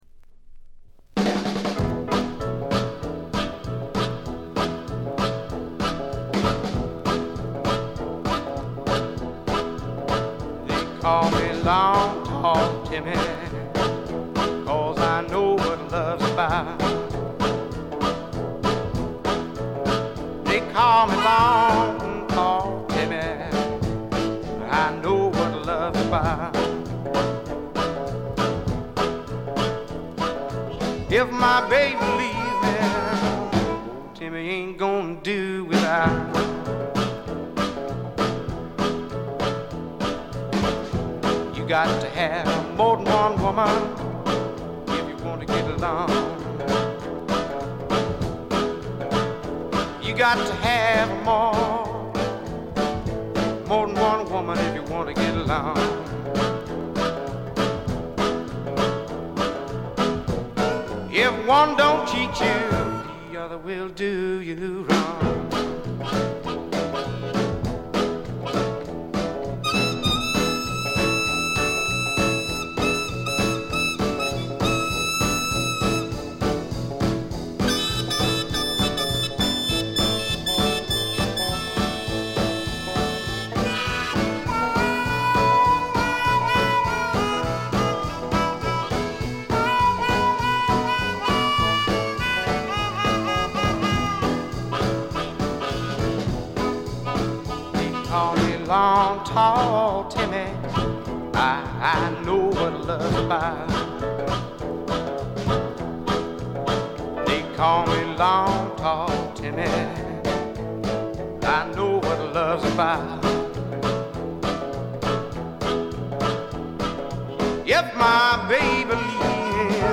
B1序盤プツ音1回。
デモと言ってもブルース、R&B色が色濃い素晴らしい演奏を聴かせます。
試聴曲は現品からの取り込み音源です。